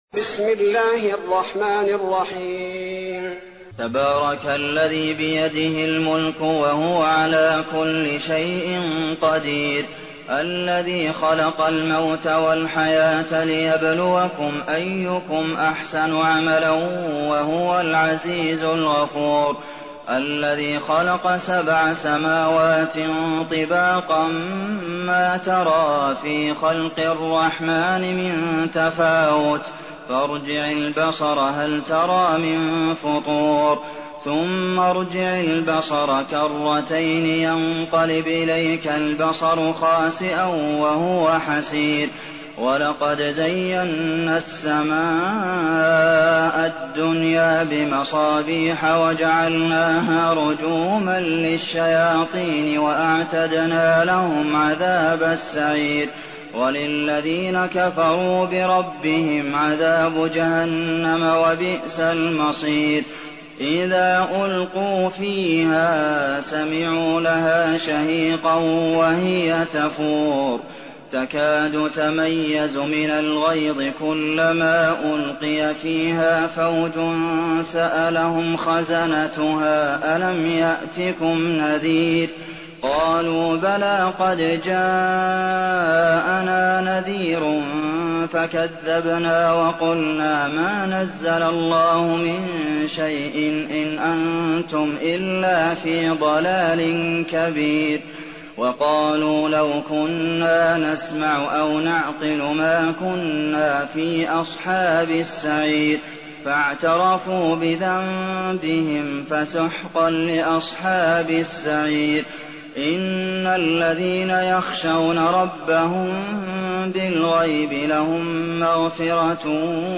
تراويح ليلة 28 رمضان 1419هـ من سورة الملك الى نوح Taraweeh 28th night Ramadan 1419H from Surah Al-Mulk to Nooh > تراويح الحرم النبوي عام 1419 🕌 > التراويح - تلاوات الحرمين